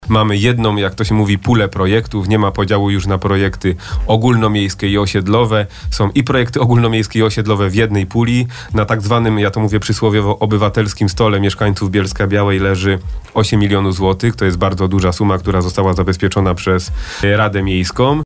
– Co bardzo istotne, można głosować zarówno elektronicznie jak i tradycyjnie. Tradycyjnie – w Centrum Organizacji Pozarządowych przy Pl. Opatrzności Bożej i w Biurze Obsługi Interesanta na Pl. Ratuszowym 6 – mówił na naszej antenie Maksymilian Pryga, radny Rady Miejskiej w Bielsku-Białej.